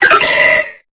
Cri de Pitrouille dans Pokémon Épée et Bouclier.